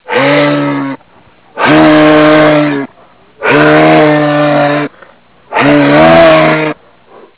Wildlife Sounds
moose1.wav